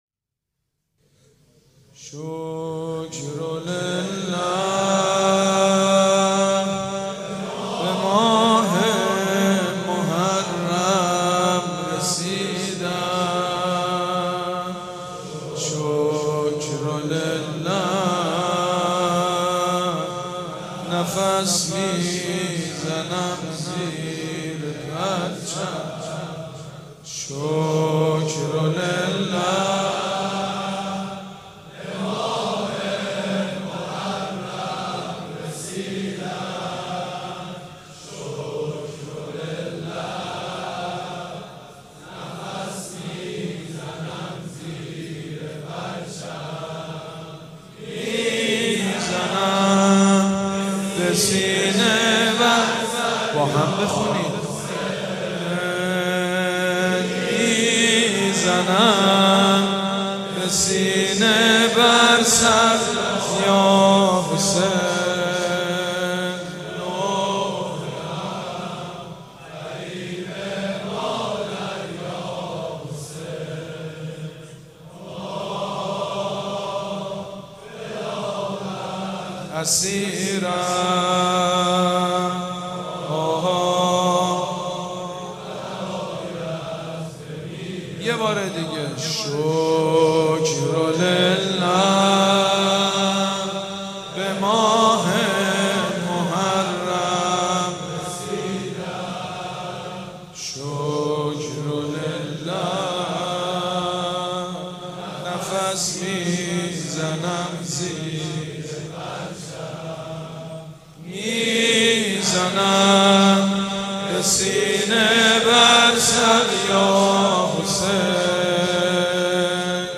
مجموعه مراسم بنی فاطمه در شب تاسوعای 93